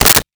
Switchboard Telephone Receiver Up 02
Switchboard Telephone Receiver Up 02.wav